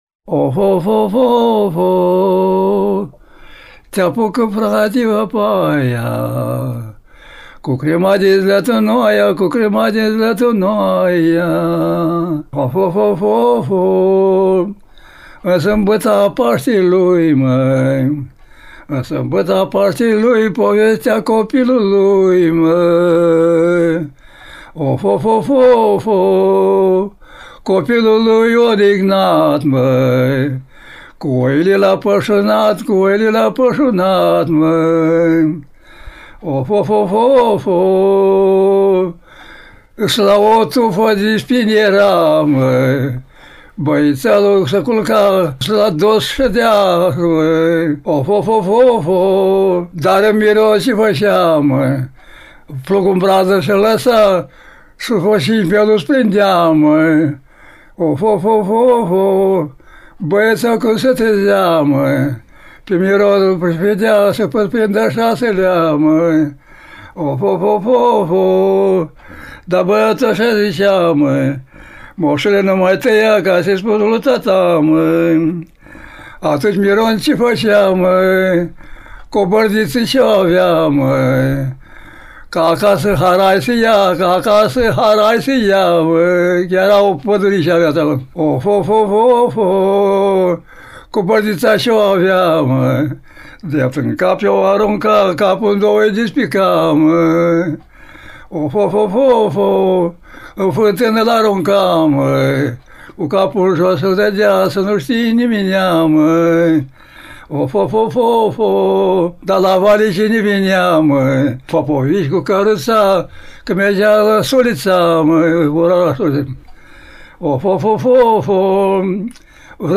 rapsodul popular
a obținut premiul I. A impresionat publicul și juriul cu balada